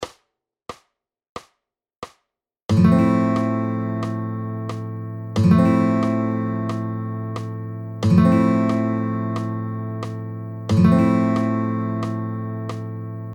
EX2、EX３はリズムは同じでコードが変わります。
※エクササイズの際のコードストロークは全てダウンストロークで演奏しましょう。
EX２　Fコードで全音符で弾いてみよう